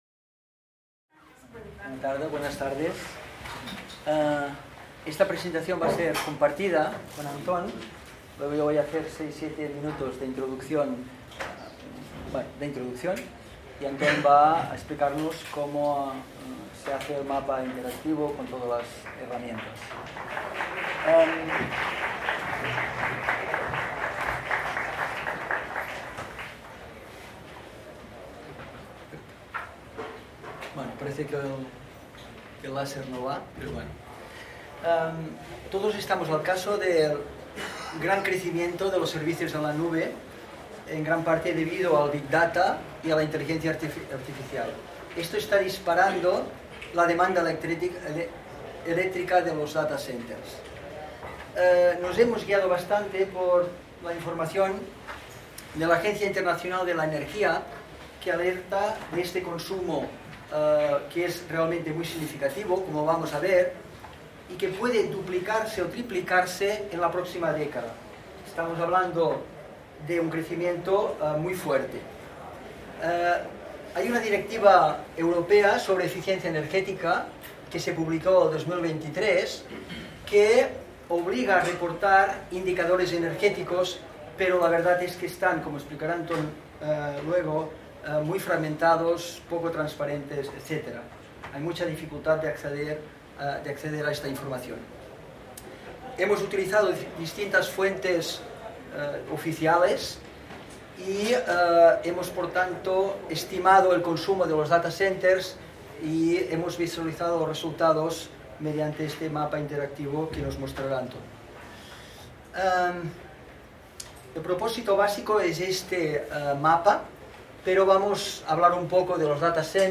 En el marc de les 18enes Jornades de SIG Lliure 2025, organitzades pel SIGTE de la Universitat de Girona